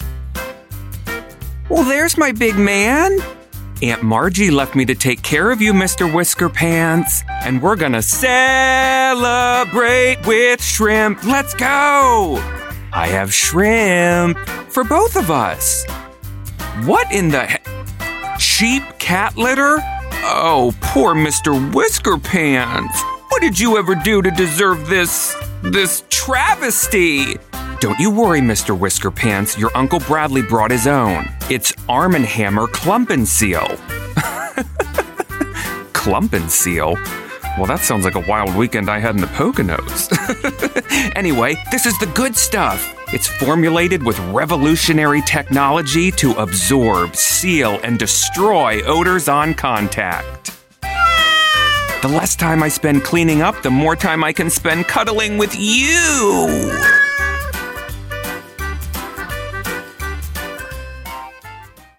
Middle Aged
My reads land with confidence and playfulness.